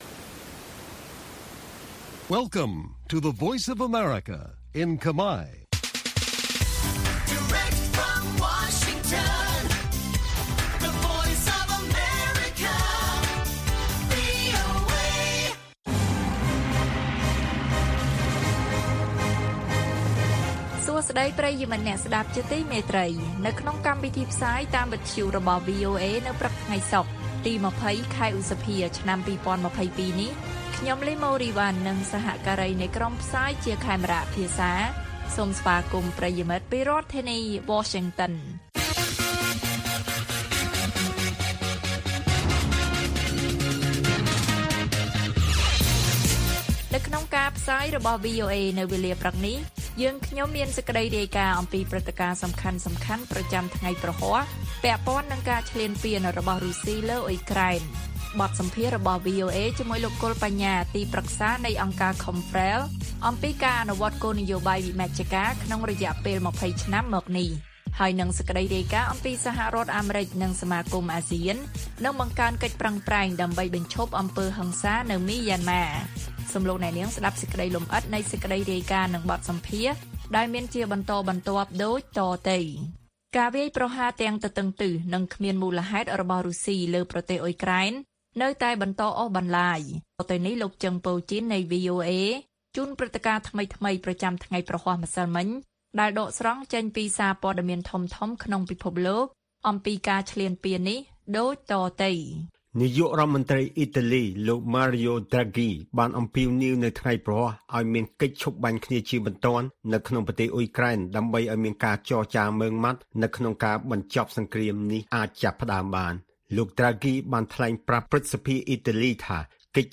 បទសម្ភាសន៍អំពីអ្នកជំនាញមើលឃើញការវិវឌ្ឍទៅមុខតិចតួចក្នុងការអនុវត្តវិមជ្ឈការរយៈពេល២០ឆ្នាំ។ អាមេរិកនិងអាស៊ាននឹងបង្កើនកិច្ចប្រឹងប្រែងដើម្បីបញ្ឈប់អំពើហិង្សានៅមីយ៉ាន់ម៉ា៕